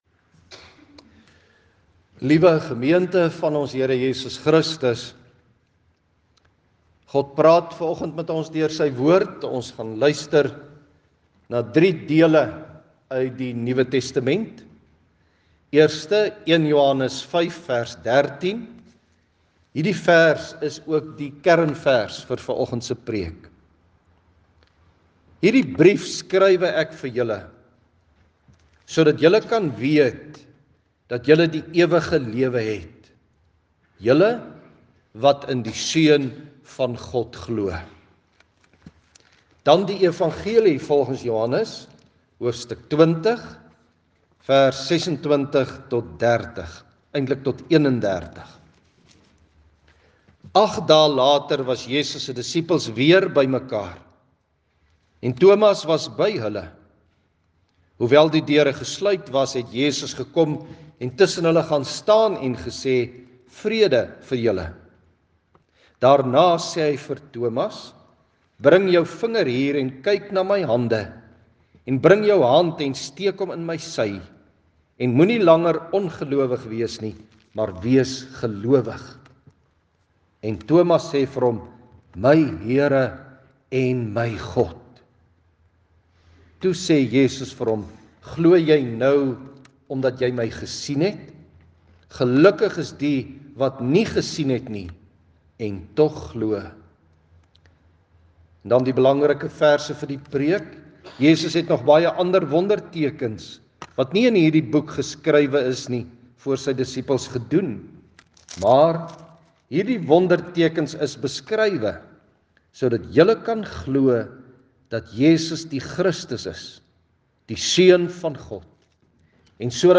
Seën: Genade, barmhartigheid en vrede vir julle van God ons Vader en Jesus Christus ons Here deur die werk van die Heilige Gees Lees: 1 Johannes 5:13; Johannes 20:26-30; Openbaring 7:9-17 Inleiding: Die Bybel, die ware lewe, die Heilige Gees, die Seun van God, die geloof in my hart en die sekerheid in ‘n onseker wêreld, is waaroor vanoggend se preek gaan.